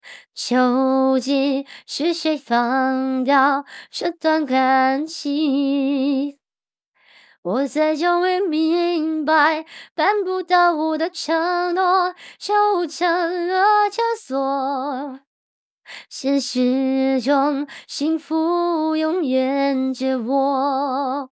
唱歌表现
萝莉音